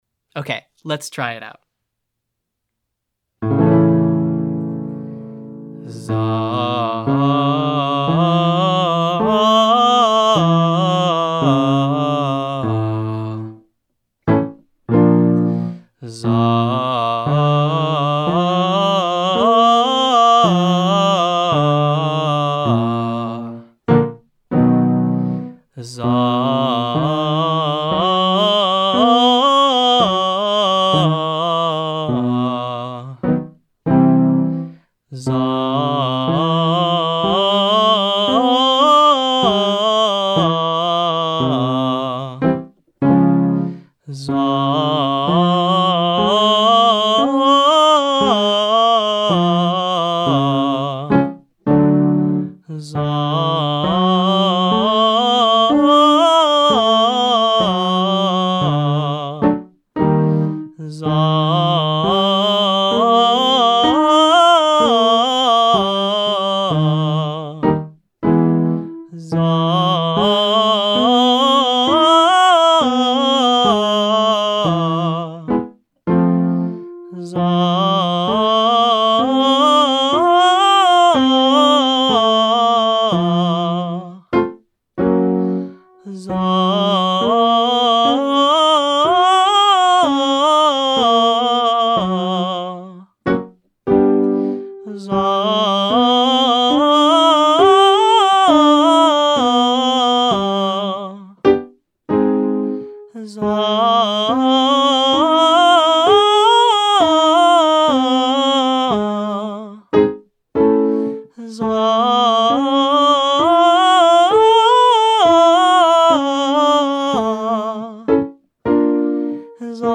Exercise 3: Zah jumping arpeggio with turnaround 2 (121,343, 565, 898 and down)
We use the arpeggio of a chord and at each interval we move up and down a scale degree.